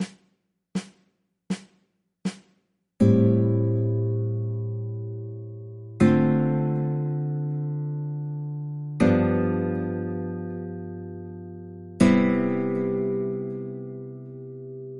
Jazz Progressions
The difference is that Jazz uses seventh and extended chords more often.
vi-ii-V-I Chord Progression
chord_progressions_vi-ii-V-I.mp3